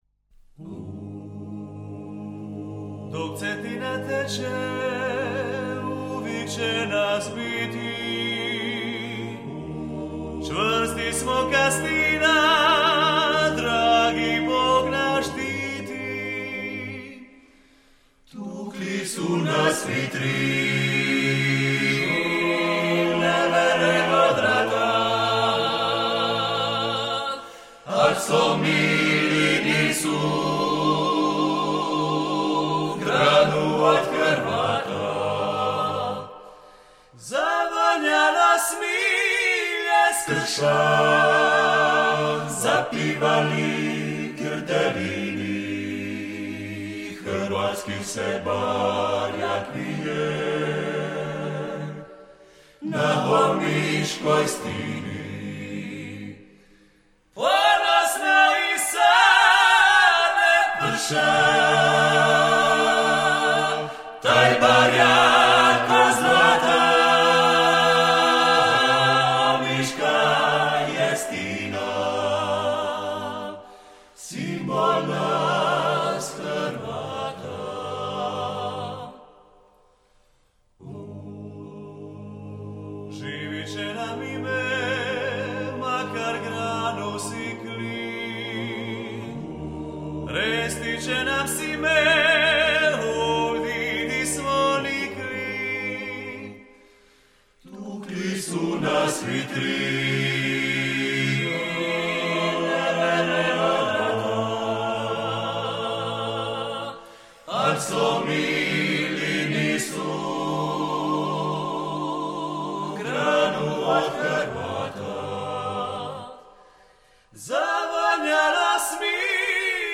Službena pjesma koju izvodi klapa 'Luka'